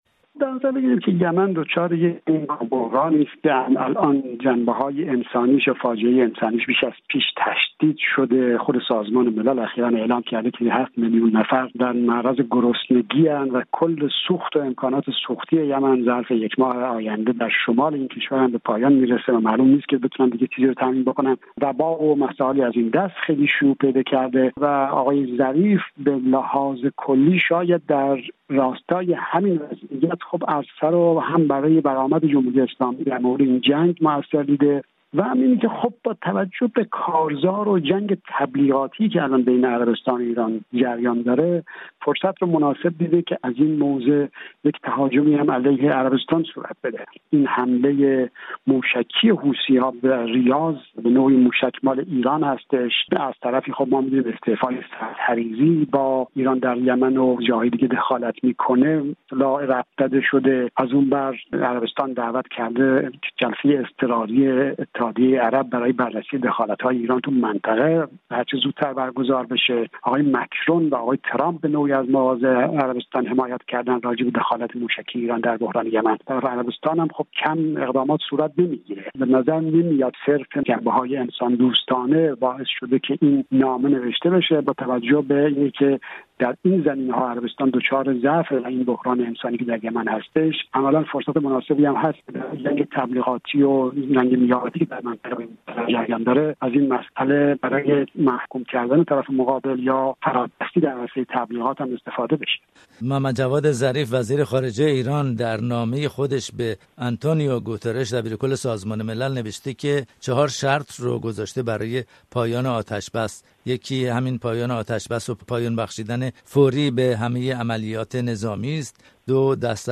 گفت وگو